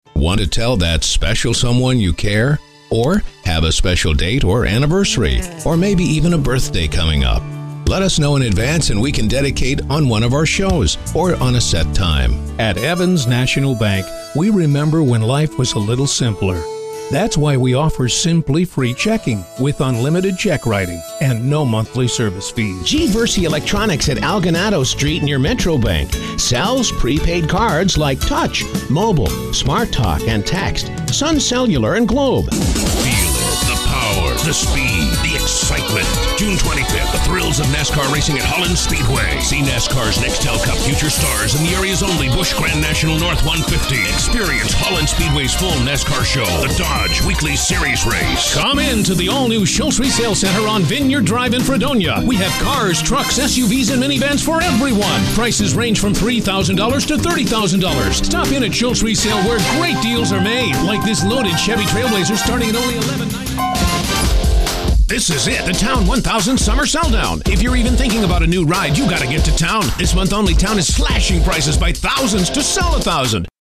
Bright and friendly, or deliberate and commanding.
Sprechprobe: Sonstiges (Muttersprache):